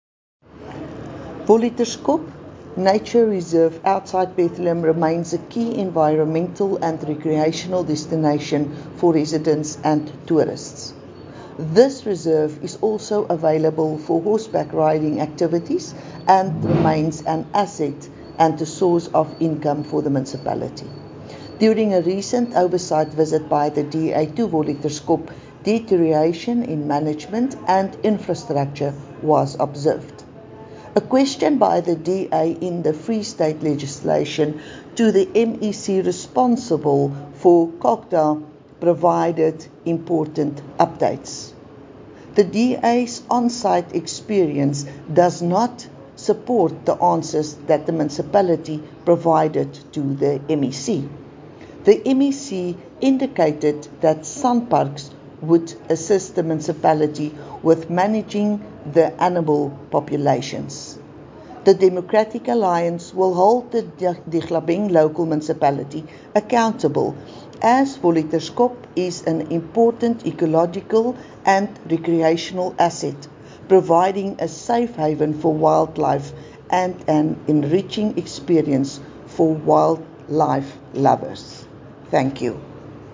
English and Afrikaans soundbites by Cllr Estie Senekal